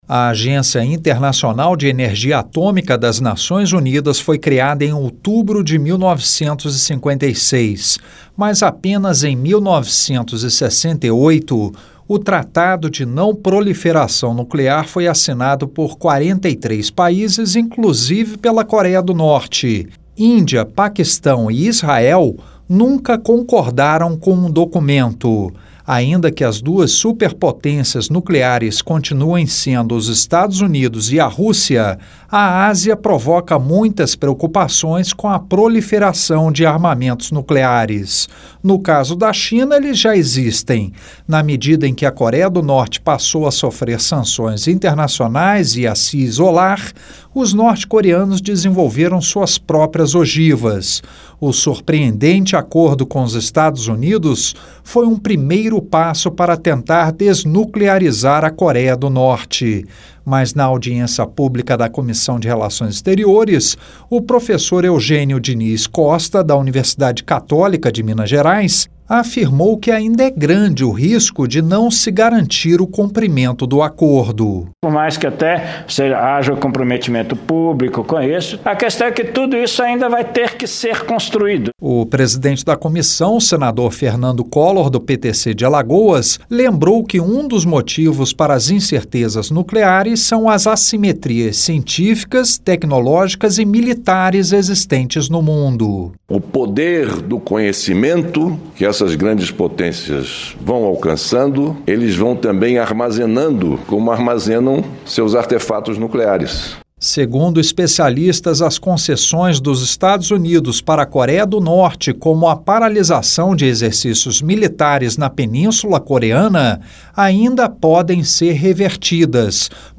Ainda é grande o risco de não se garantir o cumprimento do acordo com os Estados Unidos para desnuclearização da Coreia do Norte. O assunto foi discutido em audiência pública na Comissão de Relações Exteriores do Senado. O presidente da Comissão, Senador Fernando Collor (PTC-AL) lembrou que um dos motivos para as incertezas nucleares são as assimetrias científicas, tecnológicas e militares existentes no mundo.